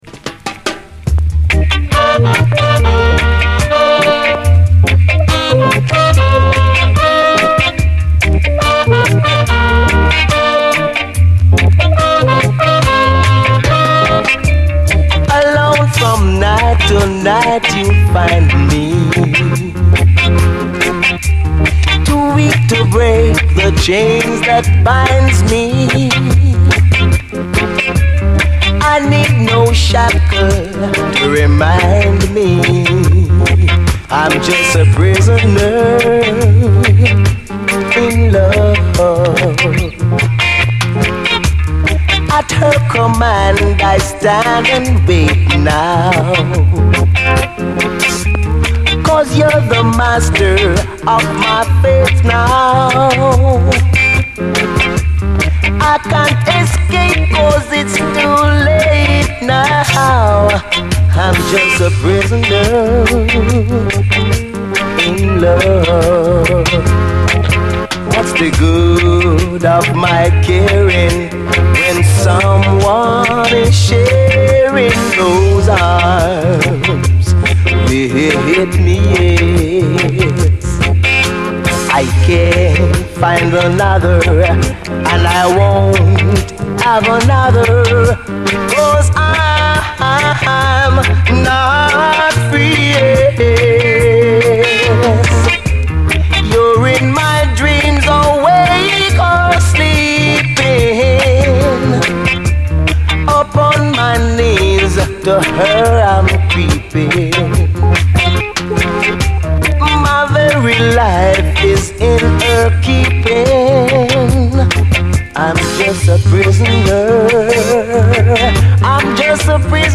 REGGAE, 7INCH
哀愁メロディーが熱くこみ上げる！
熱くこみ上げる哀愁のメロディーが胸締め付けます！サイケっぽいファズの効いたオルガンもカッコいい。